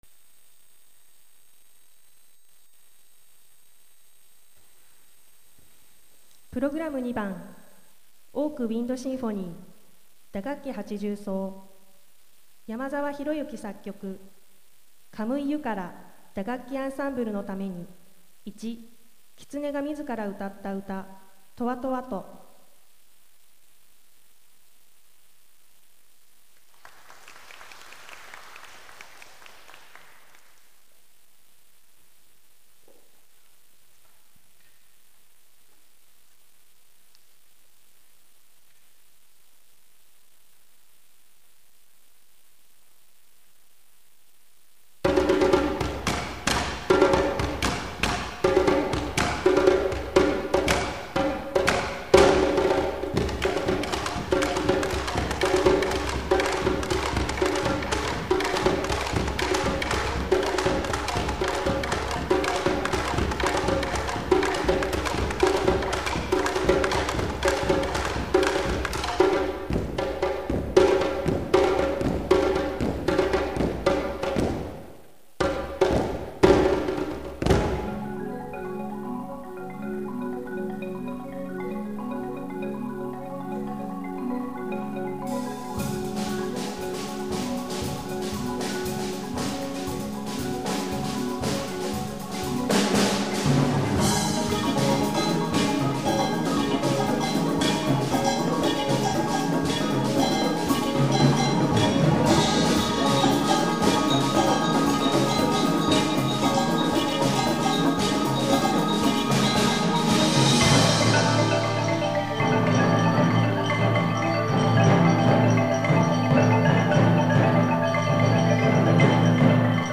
♪ 2004 神奈川県アンサンブルコンテスト 職場・一般部門予選大会
神謡〜カムイユカラ 〜打楽器アンサンブルの為に〜 1.